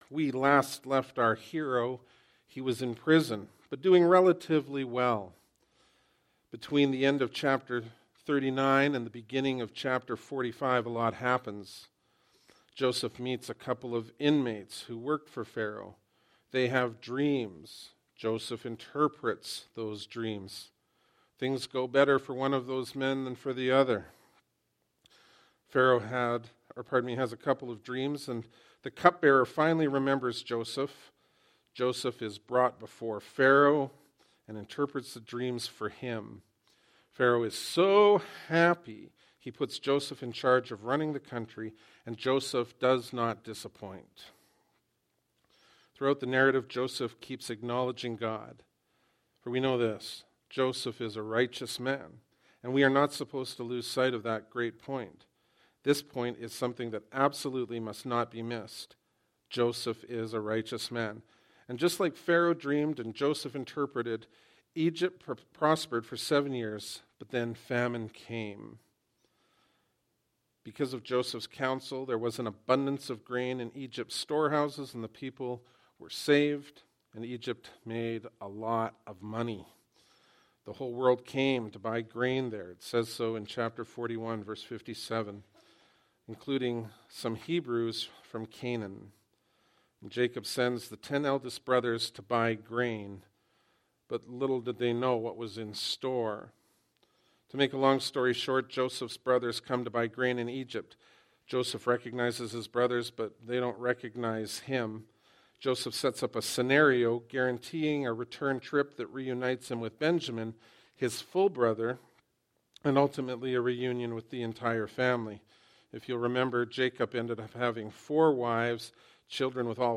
Bible Text: Genesis 45 | Preacher